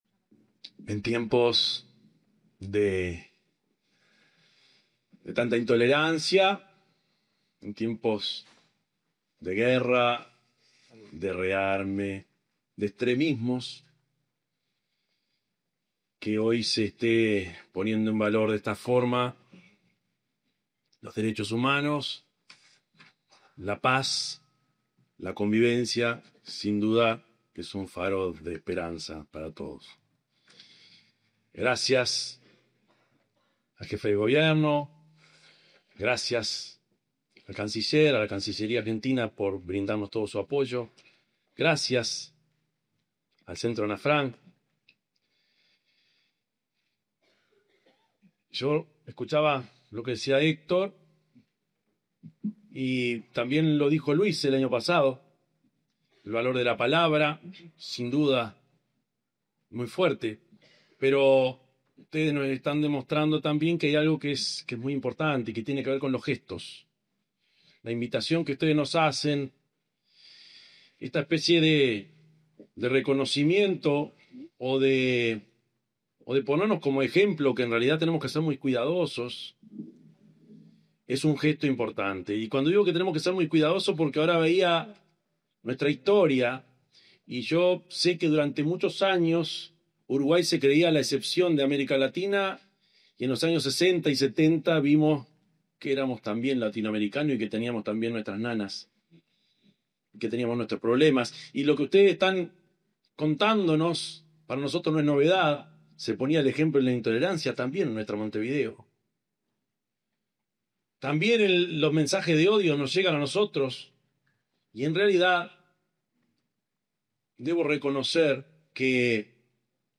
Palabras del presidente de la República, Yamandú Orsi